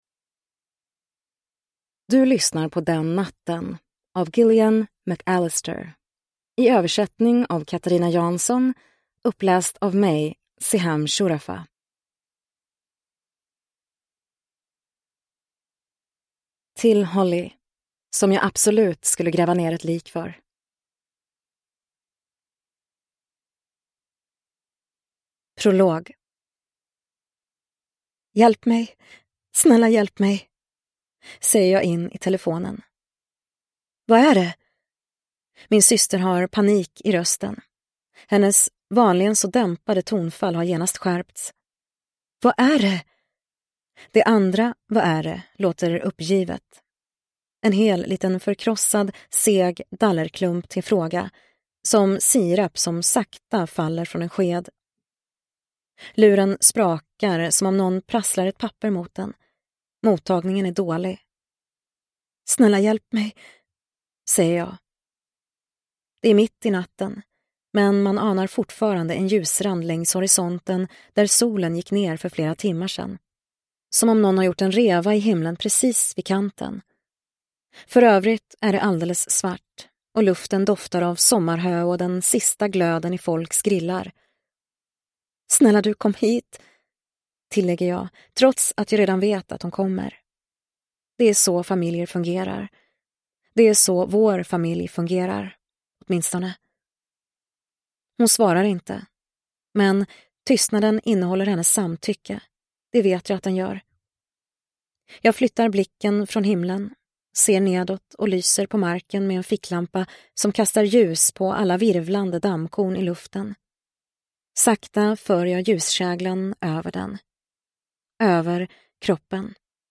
Den natten – Ljudbok – Laddas ner